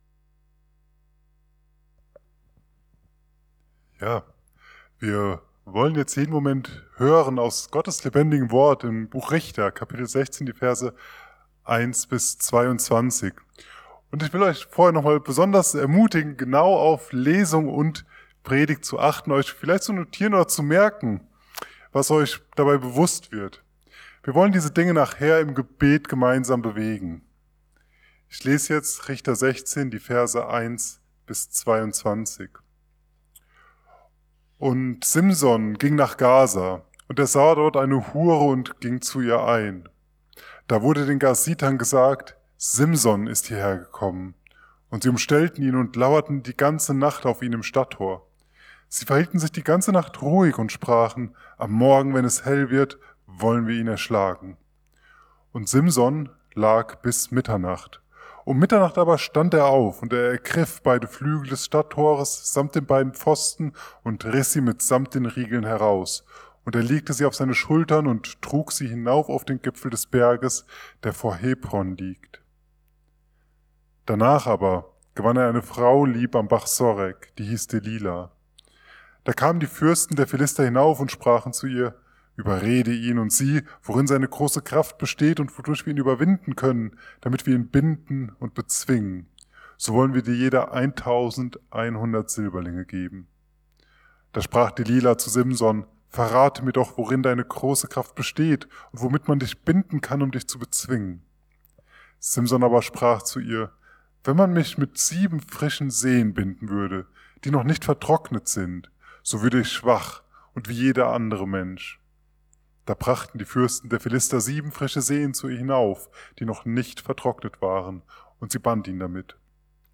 Richter 16,1-21 ~ Mittwochsgottesdienst Podcast